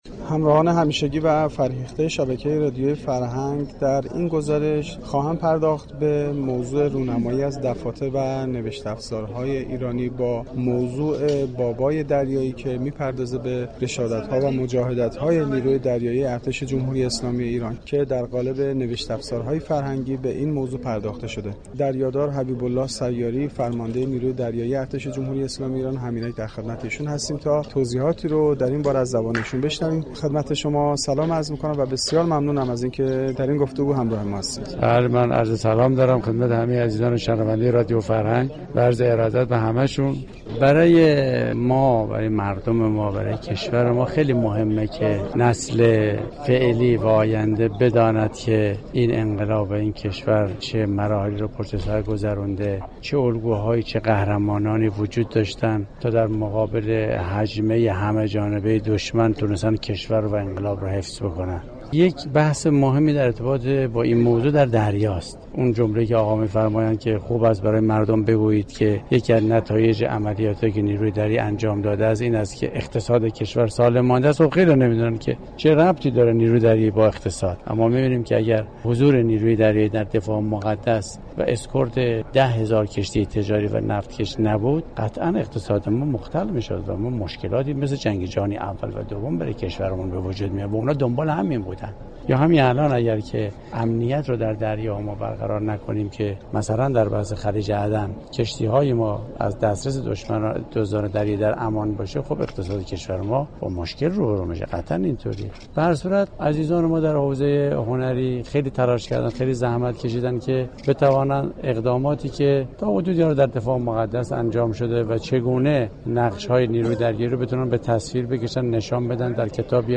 آیین رونمایی از دفاتر و نوشت‌افزارهای تخصصی ایرانی با نماد «بابای دریایی»، با حضور امیردریادار حبیب‌الله سیاری فرمانده نیروی دریایی ارتش جمهوری اسلامی ایران،برگزار شد.